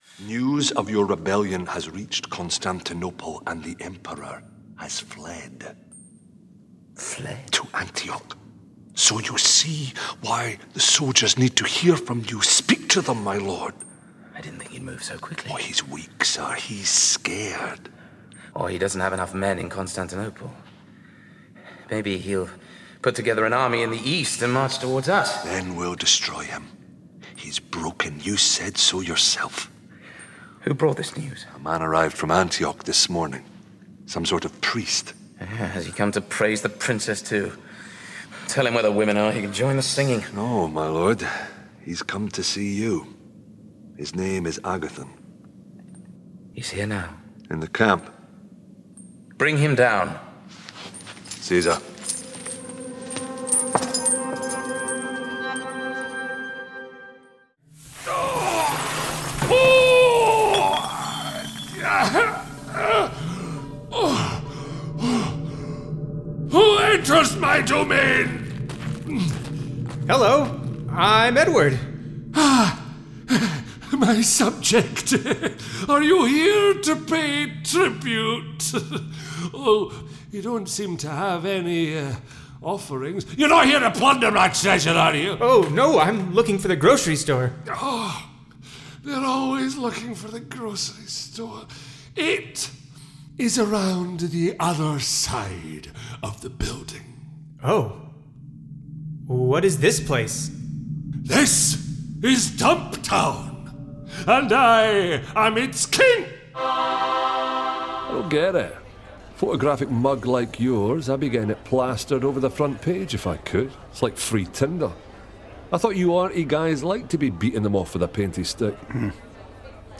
Audio Drama Showreel
He is proficient in UK and American accents, and his deep, resonant and clear voice has featured in over a hundred audiobooks to date.
Male
Authoritative
Gravelly